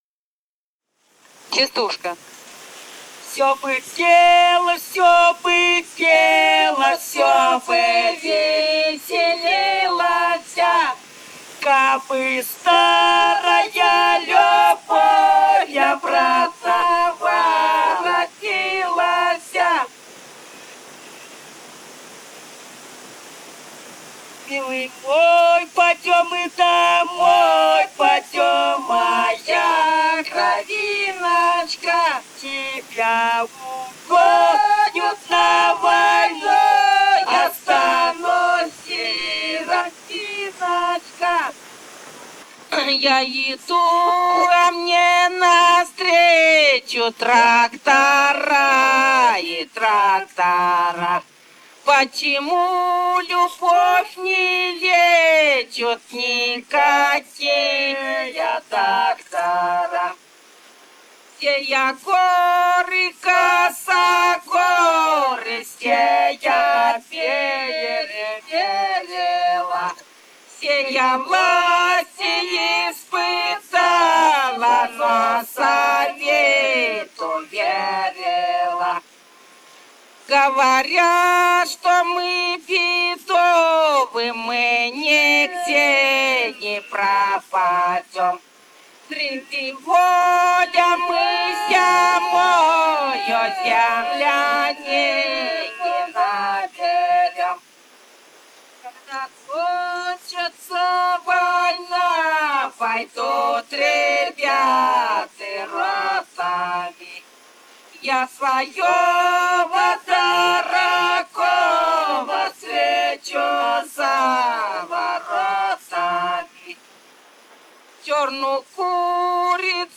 Этномузыкологические исследования и полевые материалы
«Всё бы пела, всё бы пела» (частушки).
Бурятия, с. Желтура Джидинского района, 1966 г. И0904-15